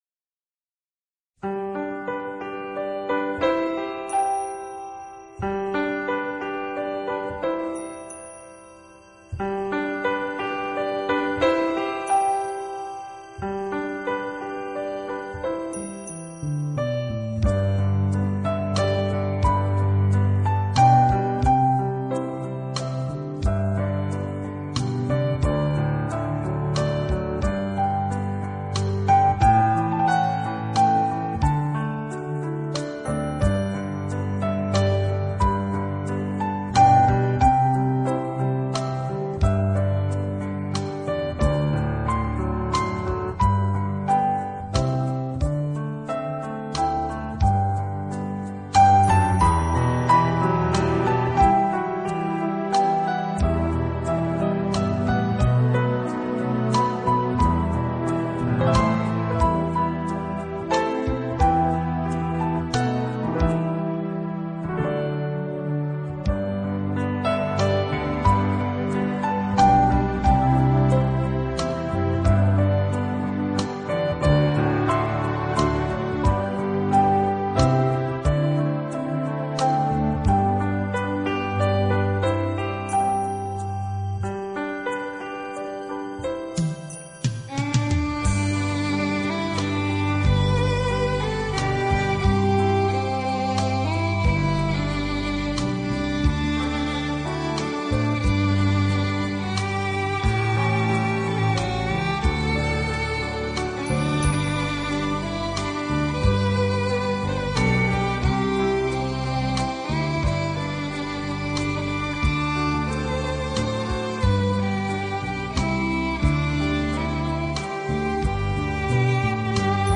透明、神秘的音乐充满了朦胧的氛围，为人们带来内心的平静。